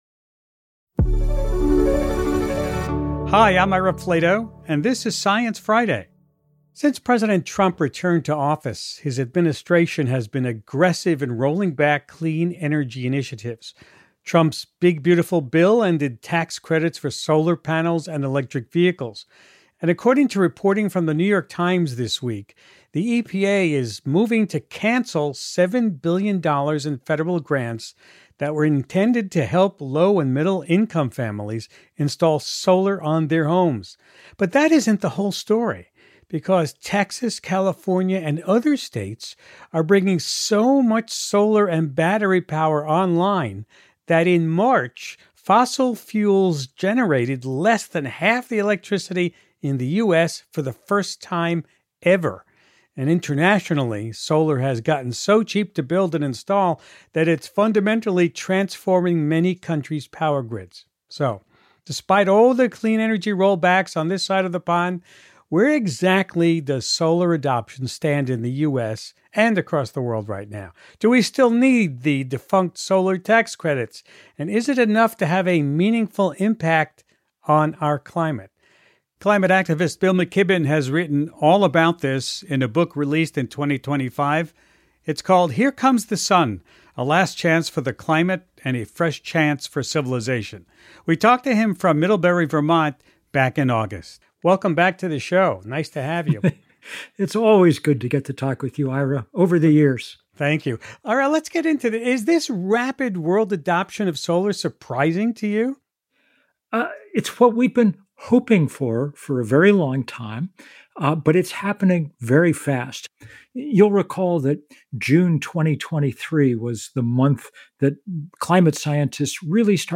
Science Friday, as heard on PRI, is a weekly discussion of the latest news in science, technology, health, and the environment hosted by Ira Flatow. Ira interviews scientists, authors, and policymakers, and listeners can call in and ask questions as well.